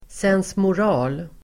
Ladda ner uttalet
Uttal: [sensmor'a:l]